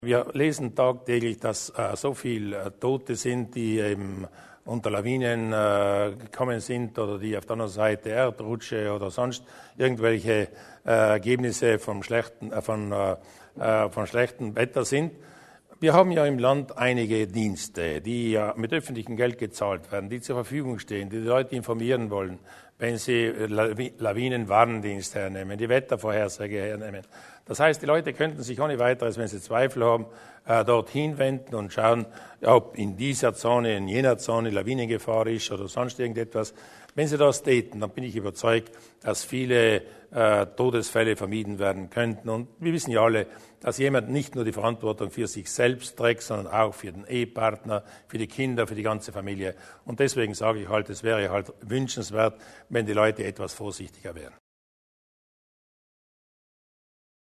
Lawinengefahr: Landeshauptmann Durnwalder zur Wichtigkeit des Lawinenlageberichts